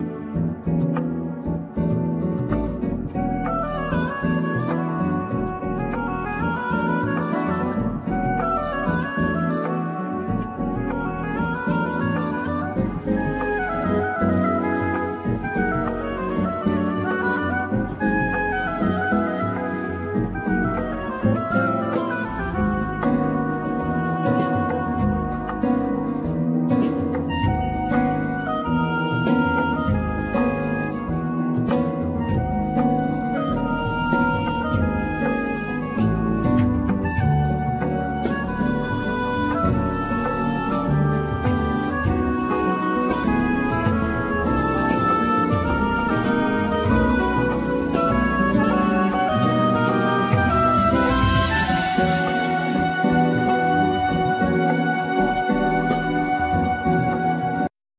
Oboe, Bass clarinet, Soprano & Sopranino Sax, Whistles
Guitars, Pianos, Synthesizers
Bass, Piano
Percussions, Voice